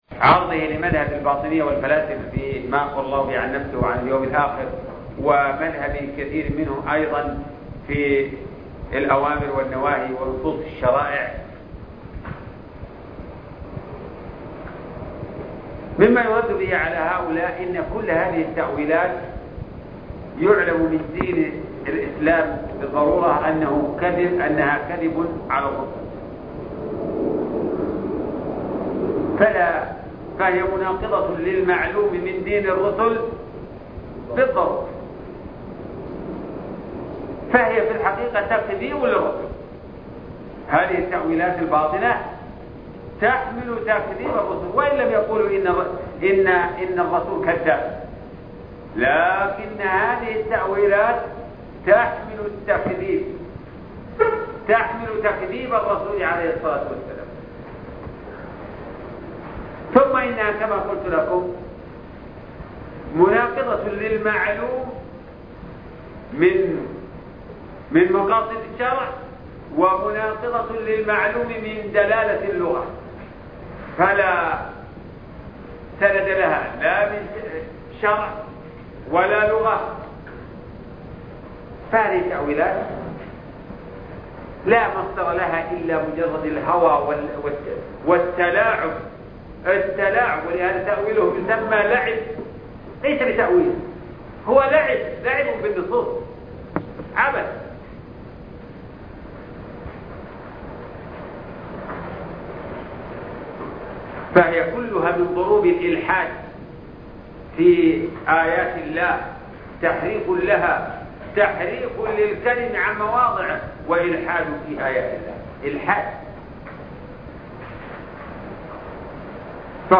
عنوان المادة شرح الرسالة التدمرية (15) الدرس الخامس عشر تاريخ التحميل السبت 19 فبراير 2022 مـ حجم المادة 42.16 ميجا بايت عدد الزيارات 211 زيارة عدد مرات الحفظ 88 مرة إستماع المادة حفظ المادة اضف تعليقك أرسل لصديق